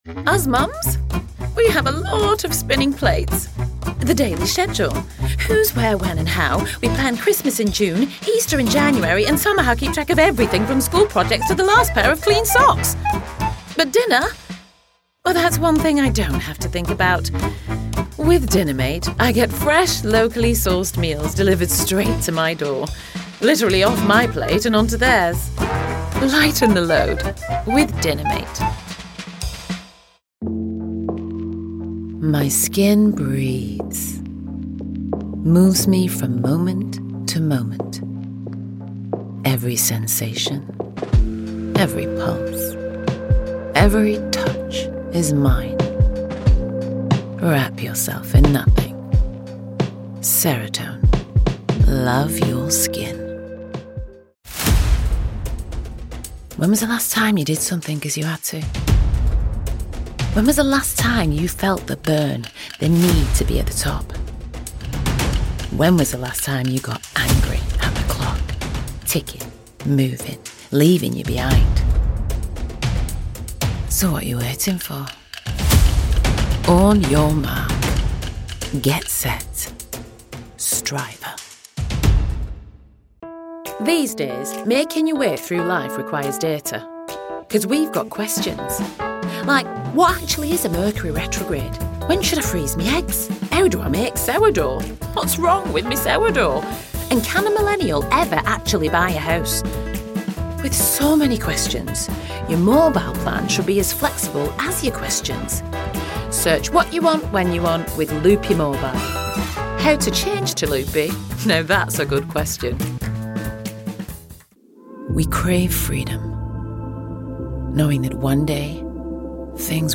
Known for her versatility, warmth, and emotional intelligence, Victoria delivers compelling performances in narration, character work, commercial reads and storytelling projects.
Victoria_Ekanoye_Full_Mix_Accent_Demo_2025.mp3